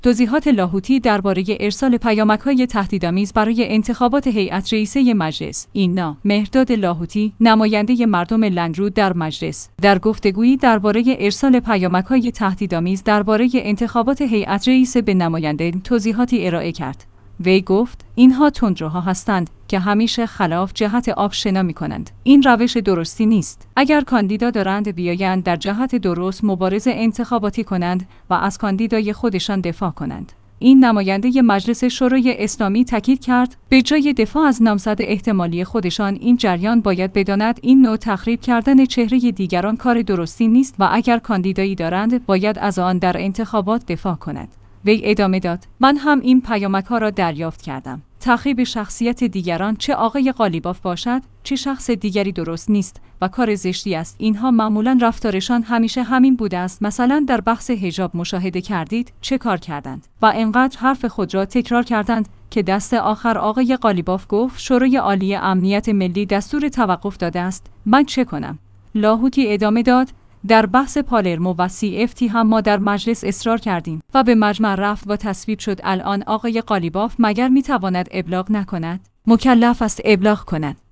ایلنا/ مهرداد لاهوتی، نماینده مردم لنگرود در مجلس، در گفتگویی درباره ارسال پیامک‌های تهدیدآمیز درباره انتخابات هیات رئیسه به نمایندگان توضیحاتی ارائه کرد. وی گفت: این‌ها تندروها هستند که همیشه خلاف جهت آب شنا می‌کنند.